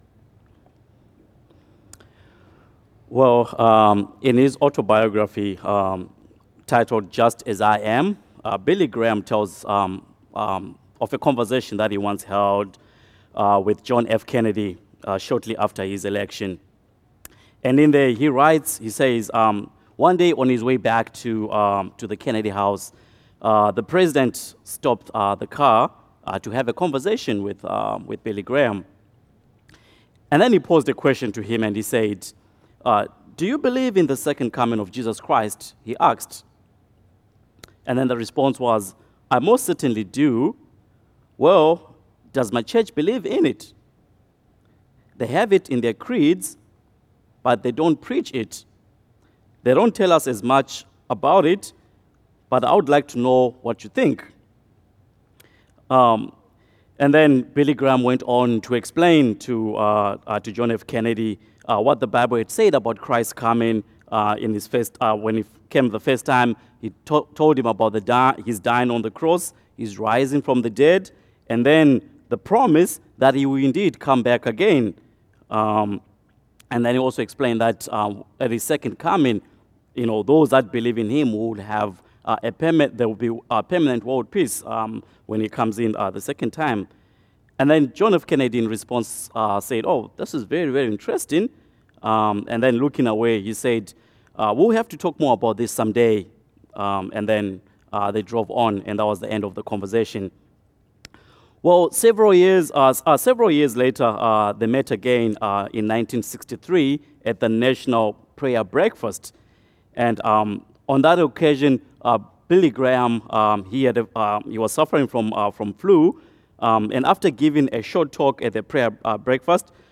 Sermons - Immanuel Church